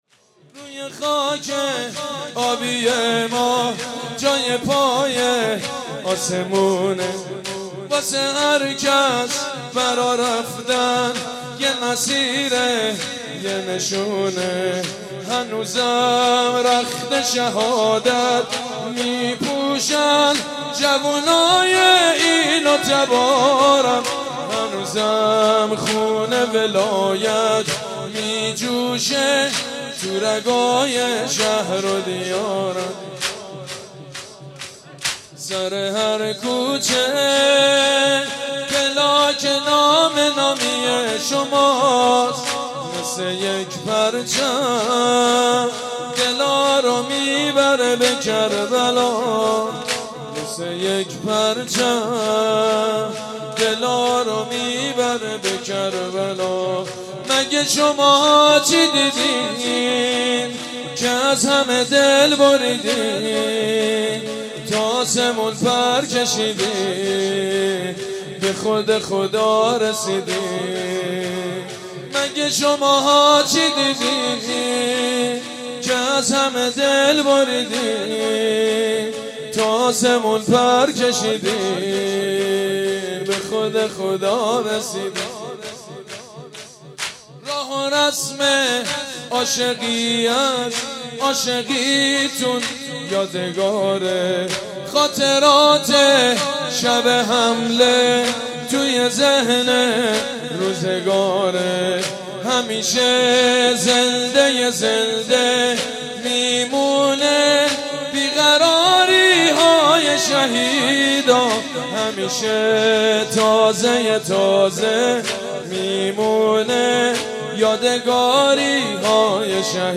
«شهادت امام جواد 1393» شور: روی خاک آبی ما جای پای آسمون